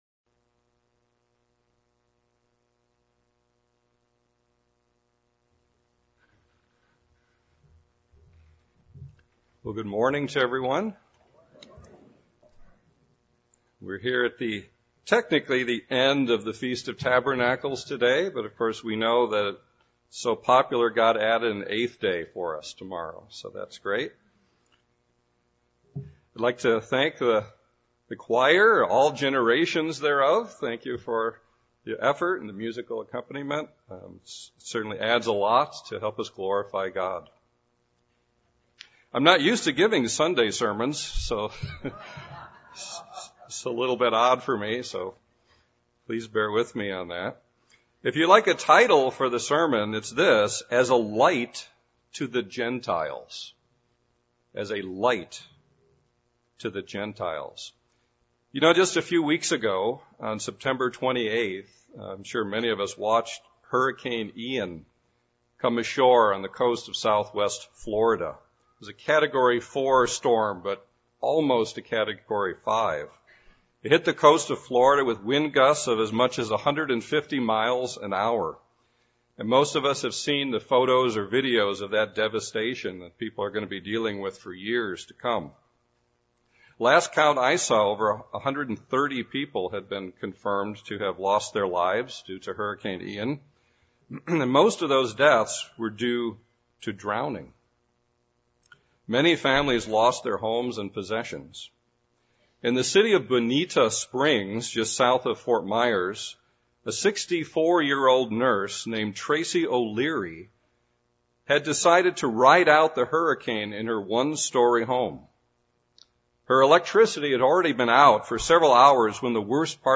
This sermon was given at the Pewaukee, Wisconsin 2022 Feast site.